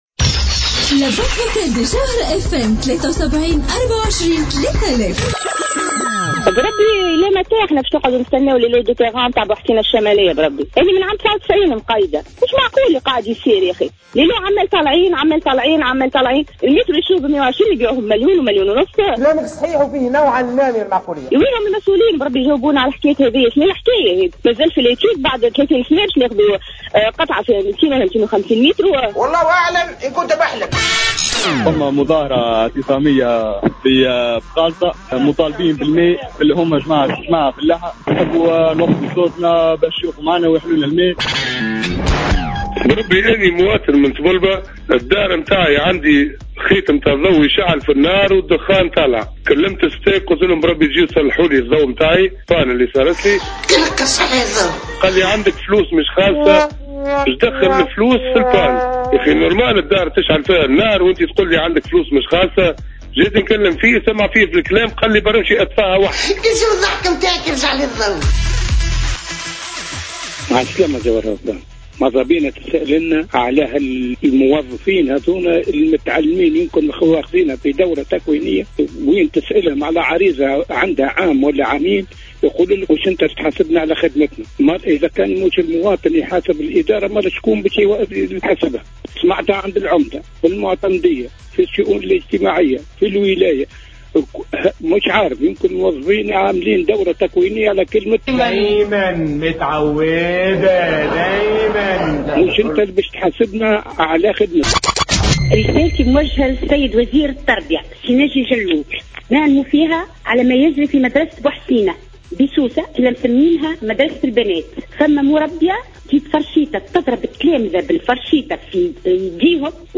مستمعة تعلّق على المطالب الاجتماعية : بربي اخدمو وبعد طالبو